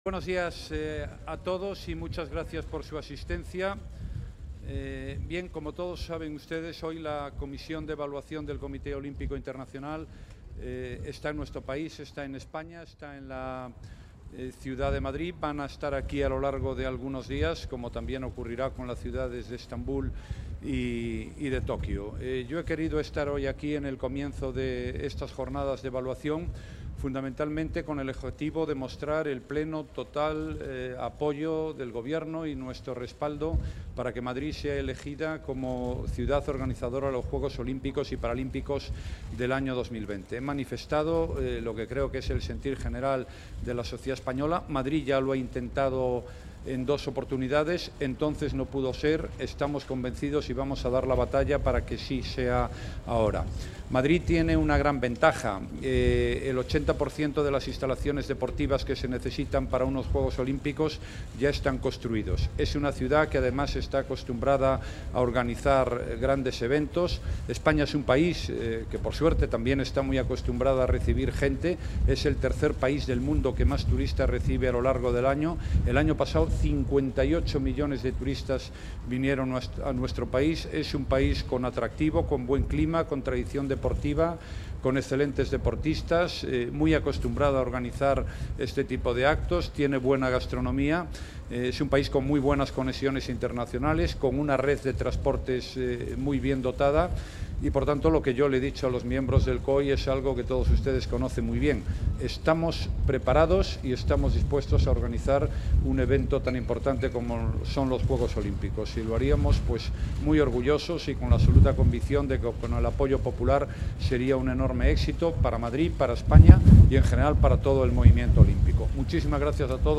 Nueva ventana:Declaraciones del presidente del Gobierno, Mariano Rajoy